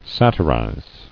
[sat·i·rize]